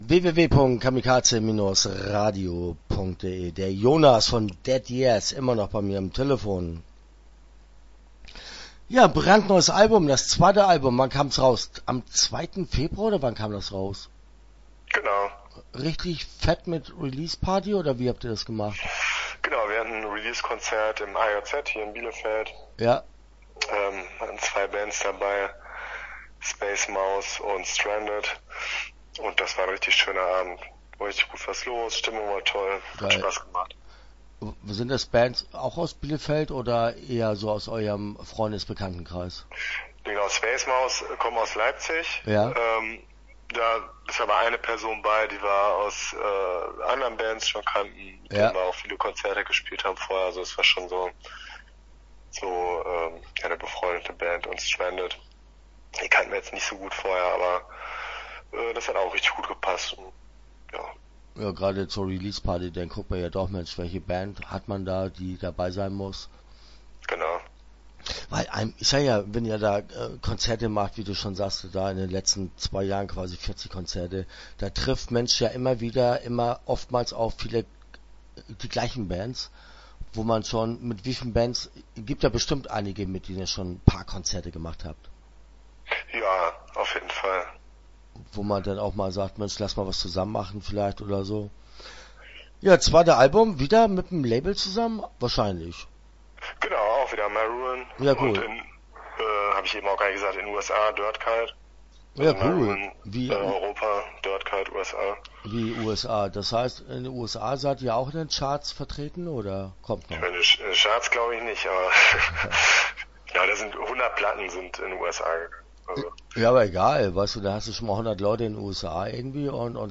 Dead Years - Interview Teil 1 (7:37)